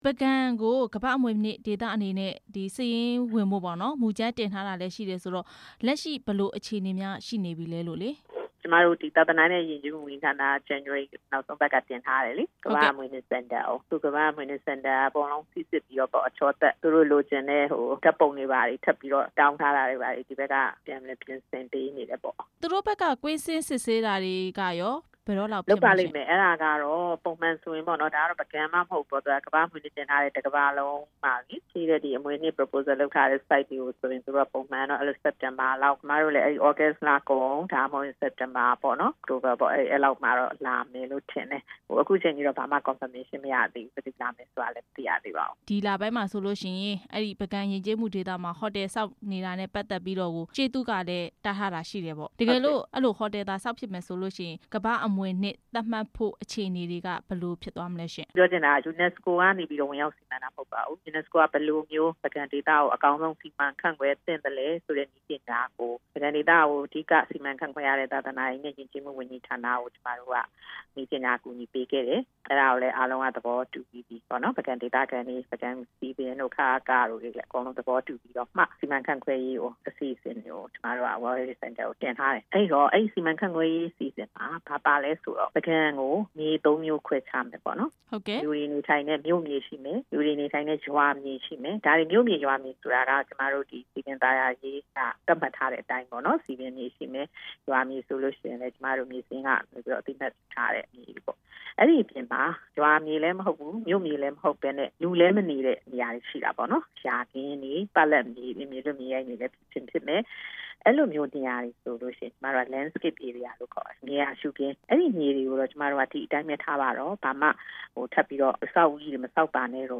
ပုဂံရှေးဟောင်းဇုန်အတွင်း ဟိုတယ်ဆောက်လုပ်မှု မေးမြန်းချက်